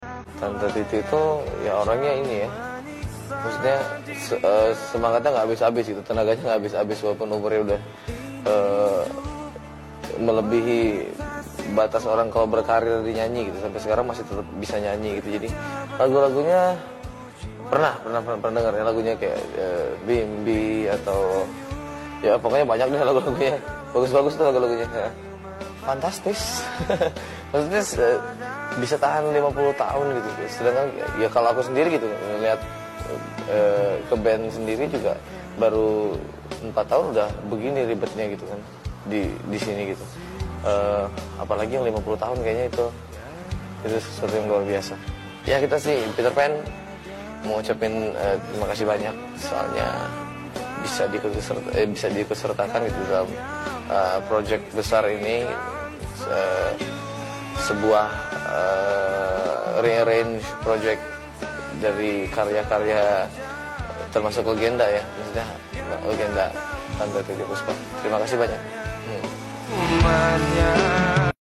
Wawancara peterpan menjelaskan tentang bermusik